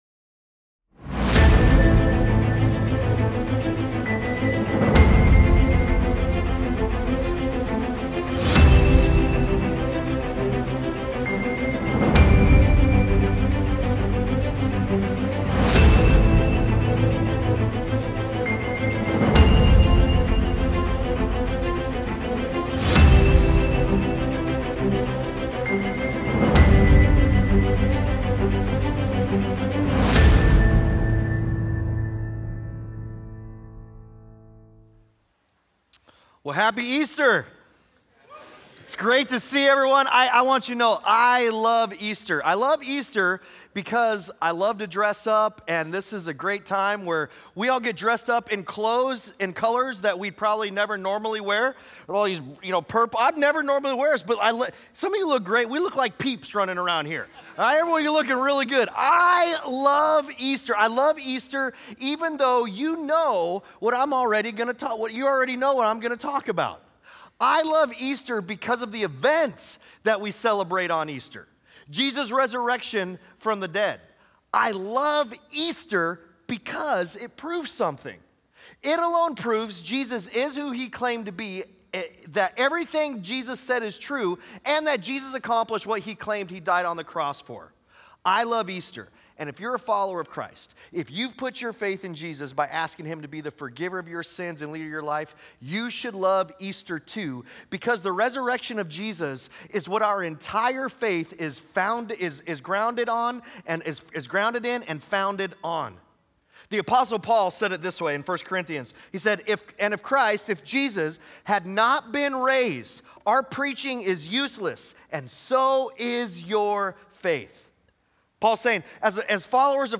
Sunday Sermons Easter: "A Mystery in History" Apr 05 2026 | 00:39:25 1x 00:00 / 00:39:25 Subscribe Share Apple Podcasts Spotify Overcast RSS Feed Share Link Embed